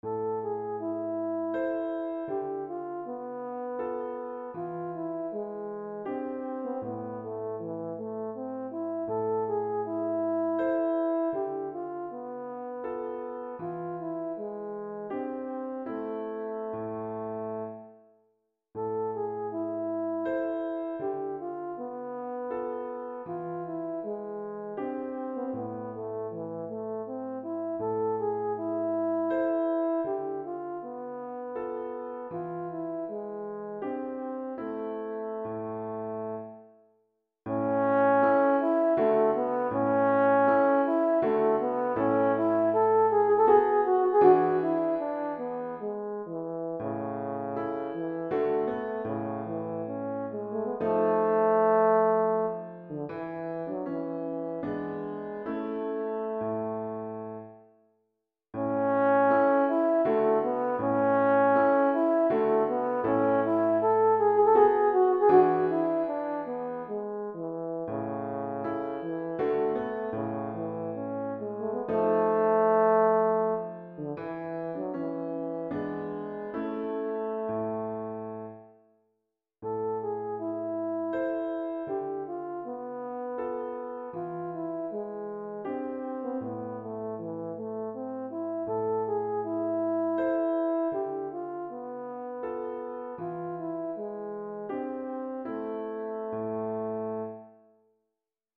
Voicing: French Horn and Piano